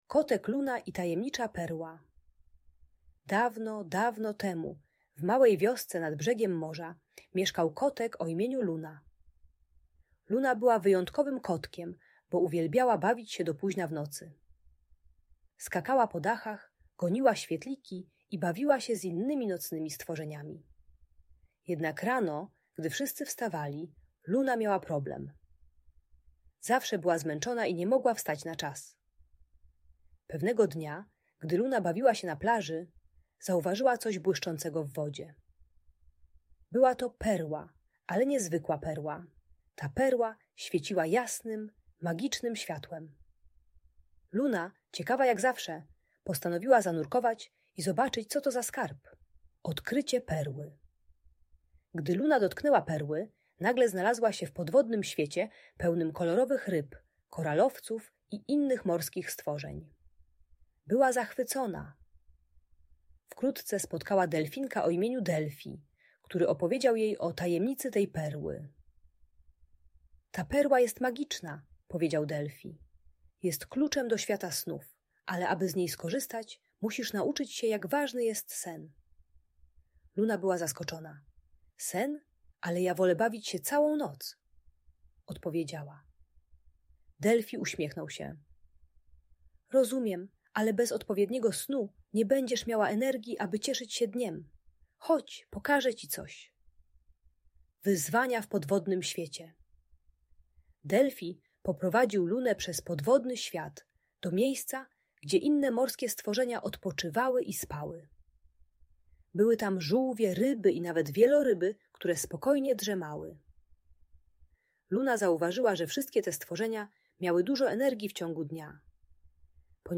Historia o Kocie Lunie i Tajemniczej Perle - Audiobajka